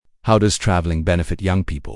Part 3 (Discussion)